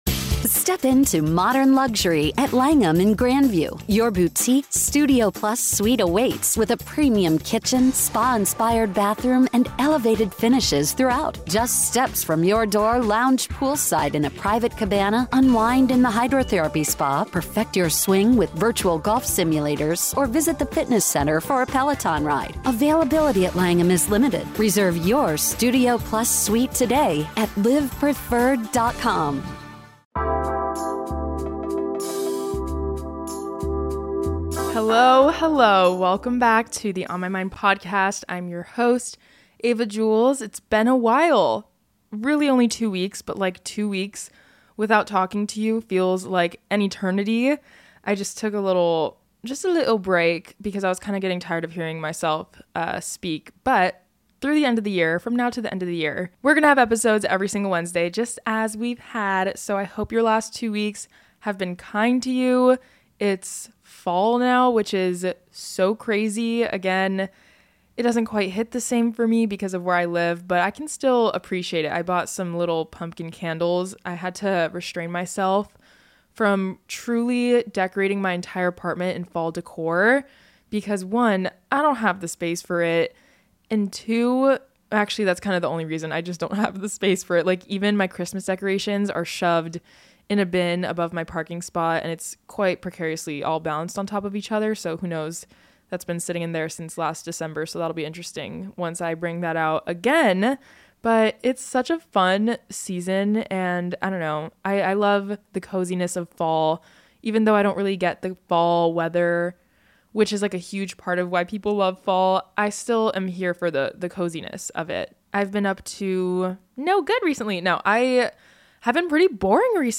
Today, I'm reading submissions from you all about a single moment or period in time that changed your life!